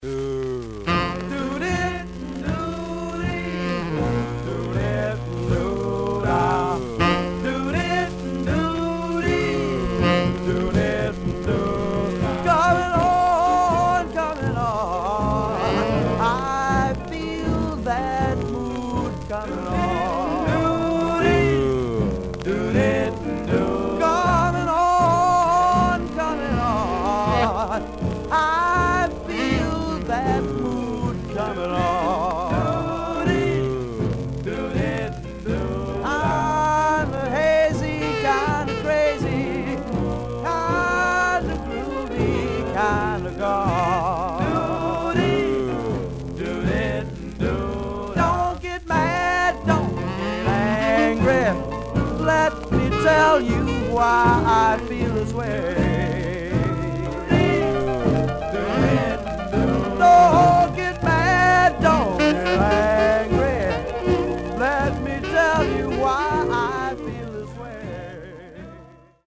Surface noise/wear
Mono
Male Black Group Condition